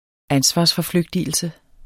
Udtale [ ˈansvɑs- ]